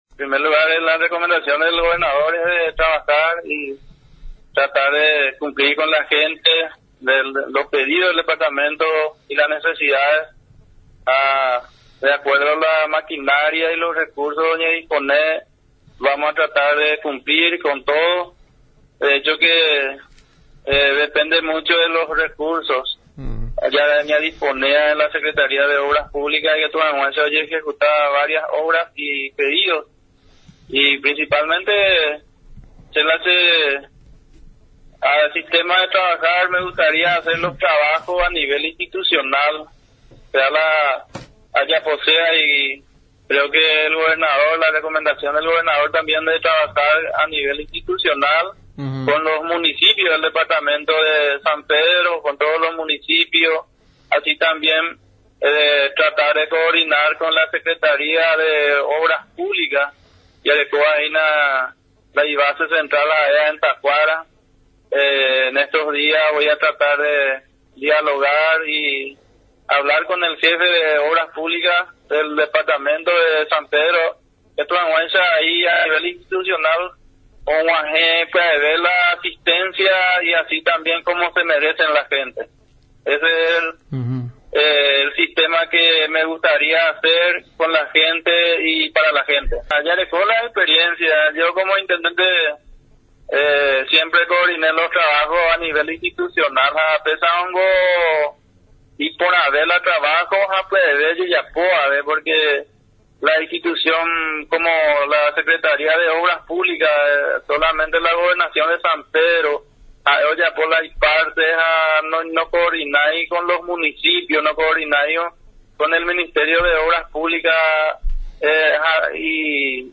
En conversación con Radio Nacional San Pedro, Silva comentó de las tareas que llevará adelante, priorizando los trabajos, que se desarrollarán de manera interinstitucional.